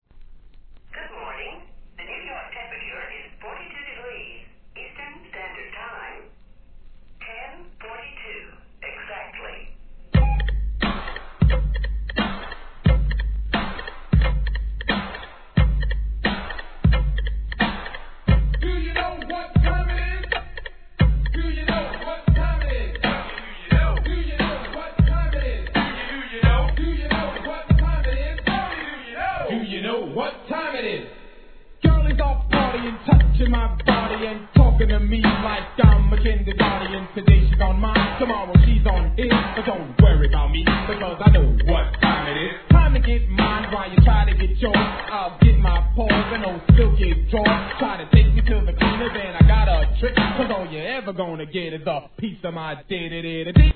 HIP HOP/R&B
'87年OLD SCHOOL!!